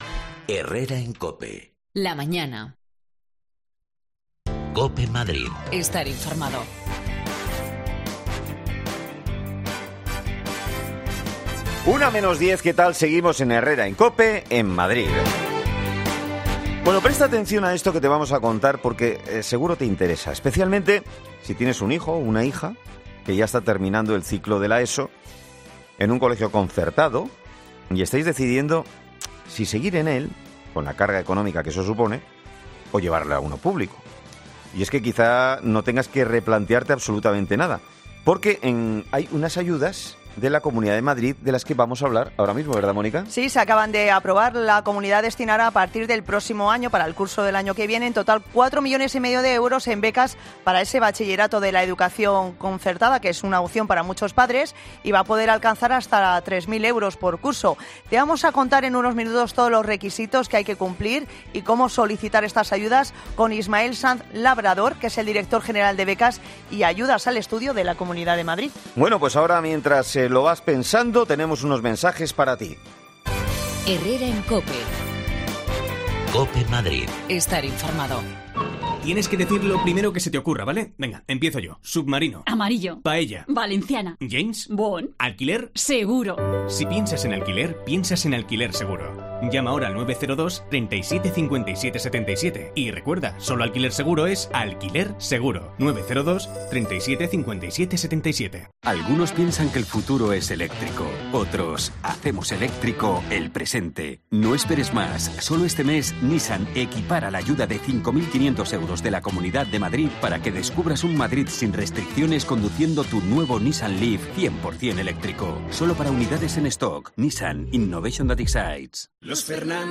En la Segunda Desconexión Local hemos hablado con Ismael Sanz Labrador, director general de Becas y Ayudas al Estudio de la Comunidad de Madrid, para conocer en que consistirán y que requisitos exigirán las nuevas becas de ayuda de bachillerato en la educación concertada.